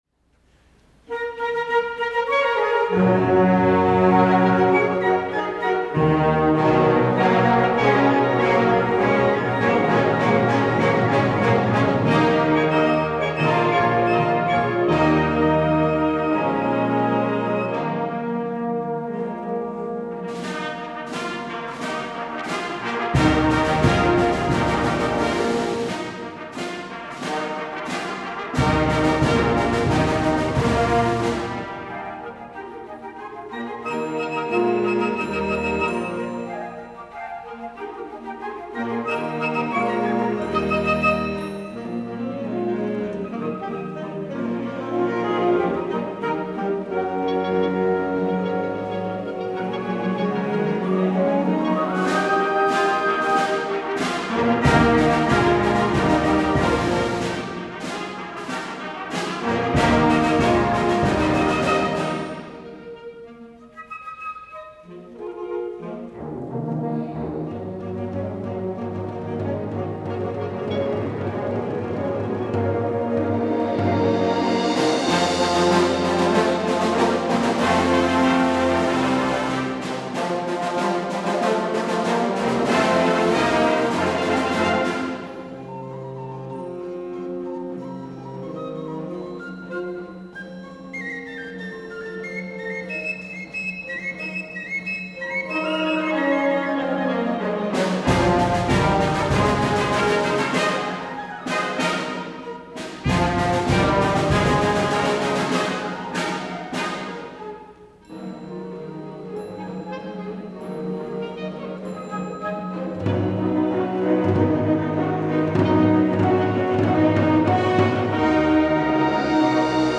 for Band (1989)